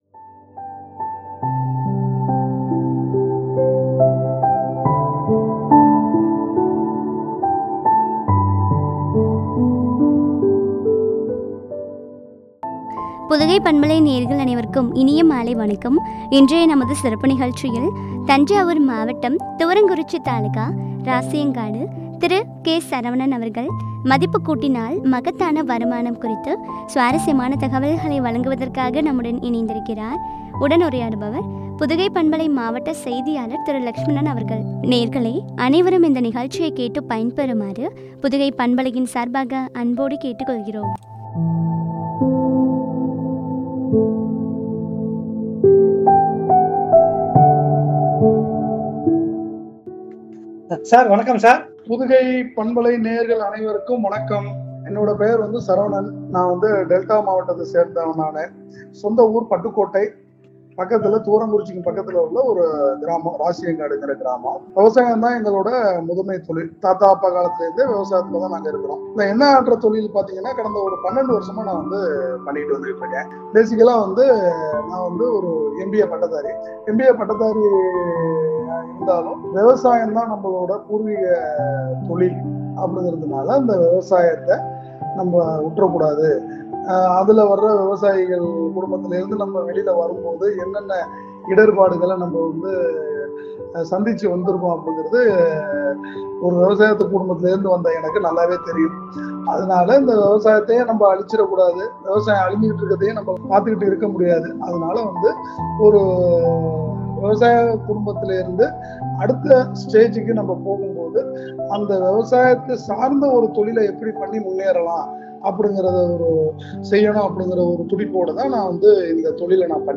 ” மதிப்புக்கூட்டினால் மகத்தான வருமானம் ” குறித்து உரையாடல்.